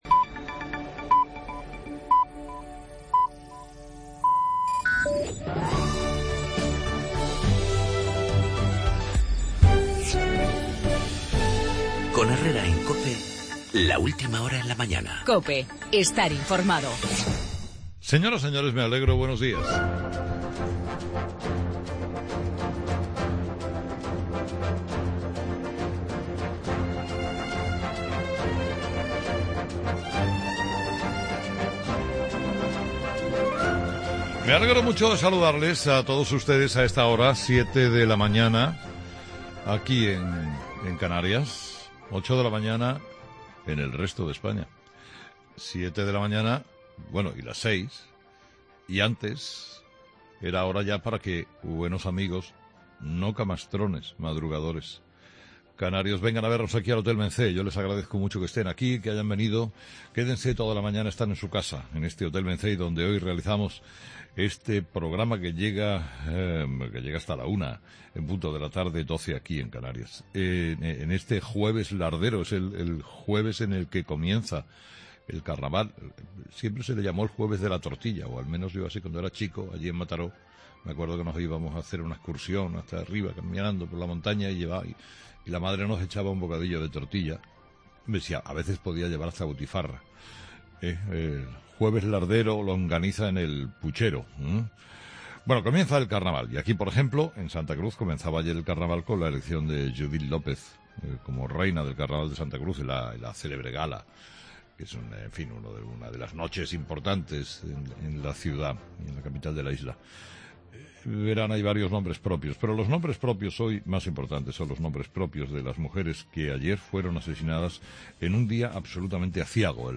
Redacción digital Madrid - Publicado el 23 feb 2017, 08:20 - Actualizado 19 mar 2023, 03:06 1 min lectura Descargar Facebook Twitter Whatsapp Telegram Enviar por email Copiar enlace El asesinato de cinco mujeres en 72 horas, la pasión de catalanes, el hallazgo de una estrella con siete planetas, en el monólogo de Carlos Herrera a las 8 de la mañana desde el Hotel Mencey de Santa Cruz de Tenerife.